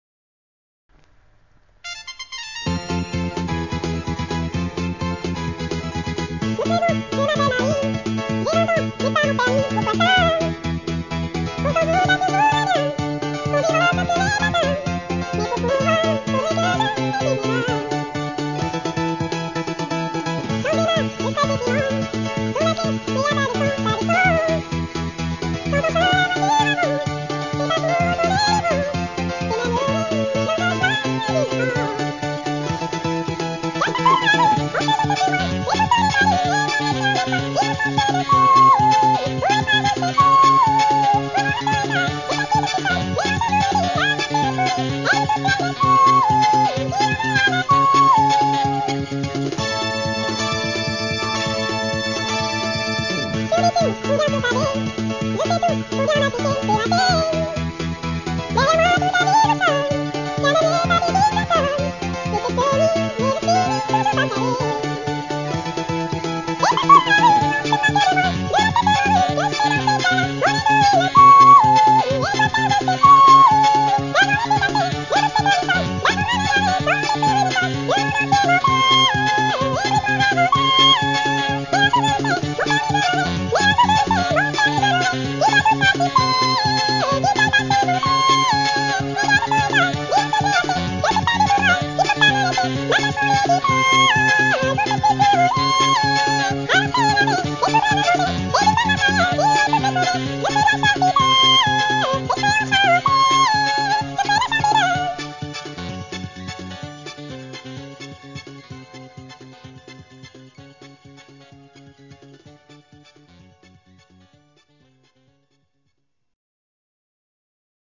version remix
genre variété locale